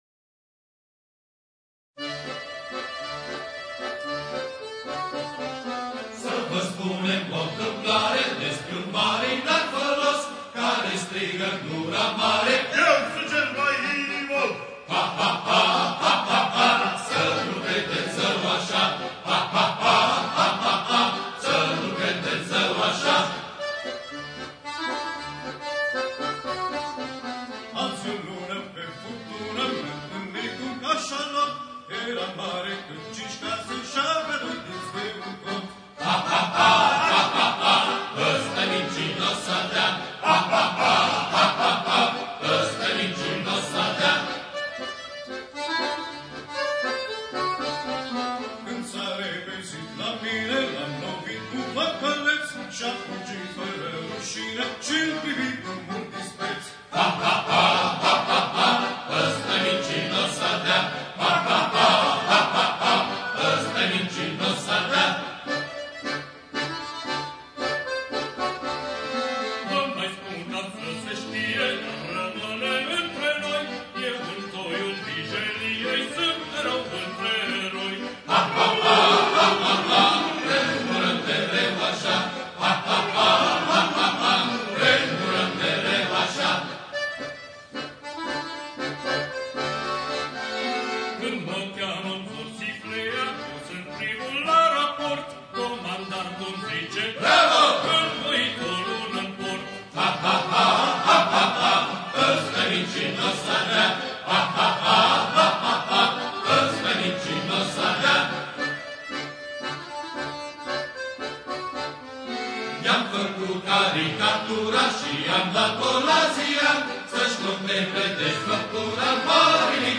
Coruri din repertoriul marinăresc
cântec satiric